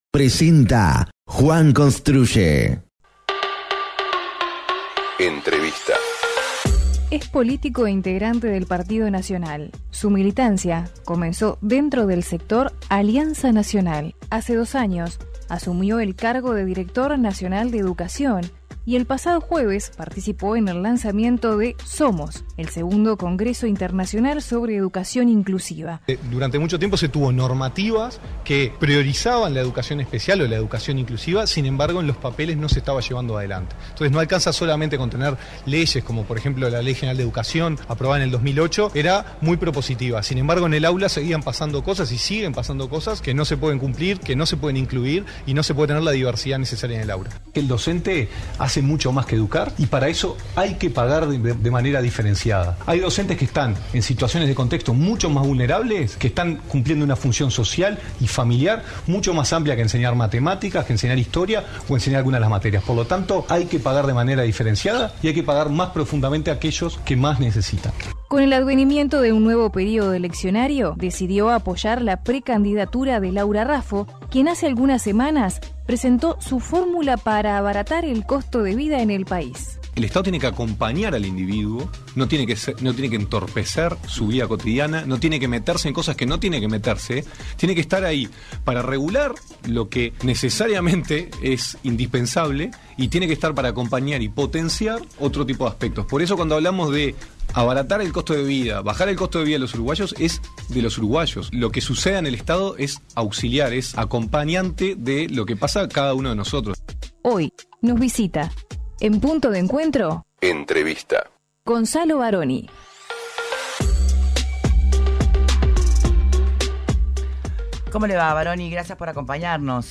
Entrevista a Gonzalo Baroni: